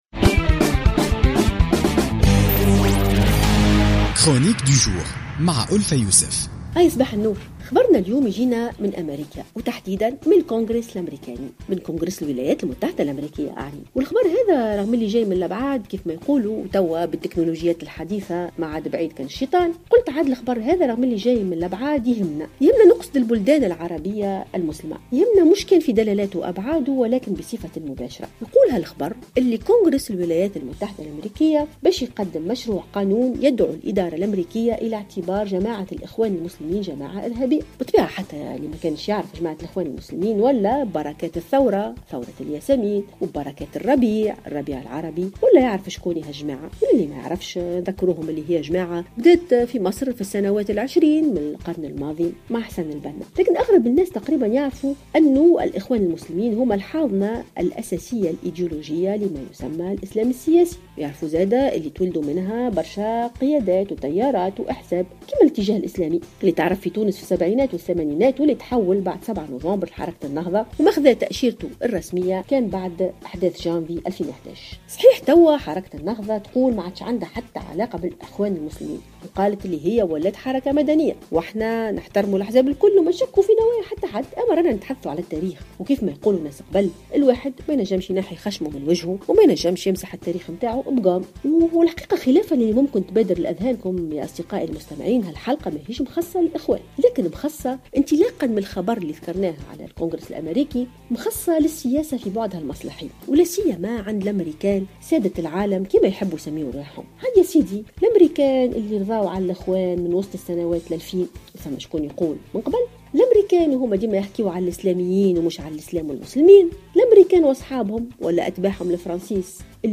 تحدثت الأستاذة والباحثة ألفة يوسف في افتتاحية اليوم الاثنين 29 فيفري 2016 عن السياسة في بعدها المصلحي وخاصة عند الأمركيين الذين ينصبون أنفسهم سادة العالم.